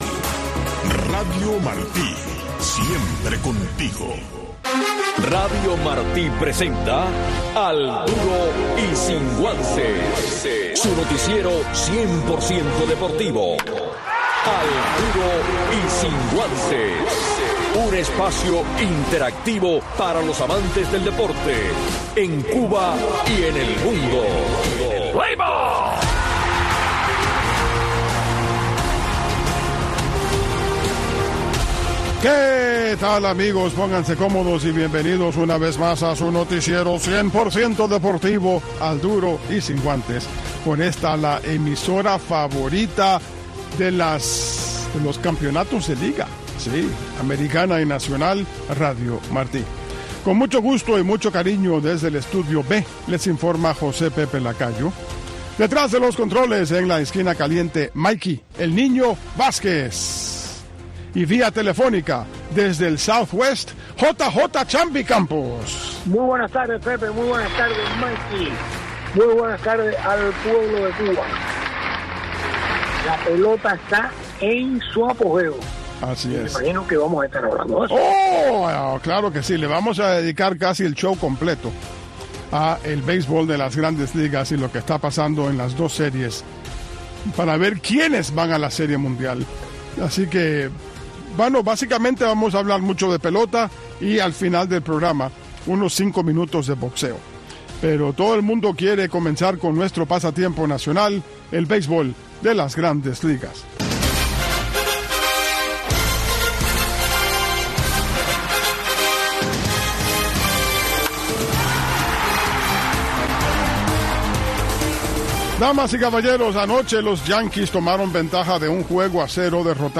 Un resumen deportivo en 60 minutos